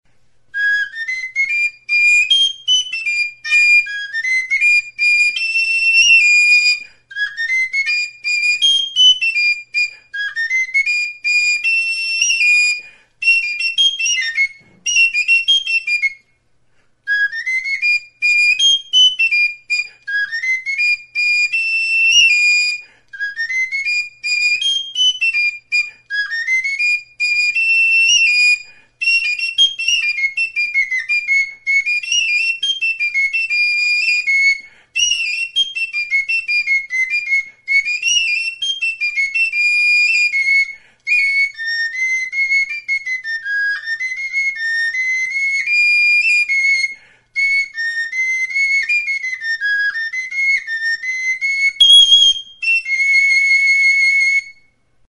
Music instrumentsXIRULA; TXIRULA
Aerophones -> Flutes -> Fipple flutes (one-handed)
Recorded with this music instrument.
EUROPE -> EUSKAL HERRIA
Hiru zuloko ahokodun flauta zuzena da.